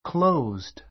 closed A1 klóuzd ク ろ ウ ズド 動詞 close 1 の過去形・過去分詞 形容詞 閉じた ; 閉鎖 へいさ された 反対語 open （開かれた） a closed door a closed door 閉ざされたドア, しまっているドア Closed today.